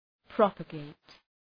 Προφορά
{‘prɒpə,geıt}
propagate.mp3